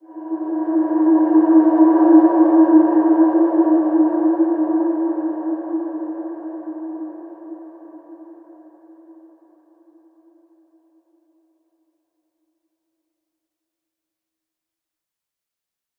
Large-Space-E4-f.wav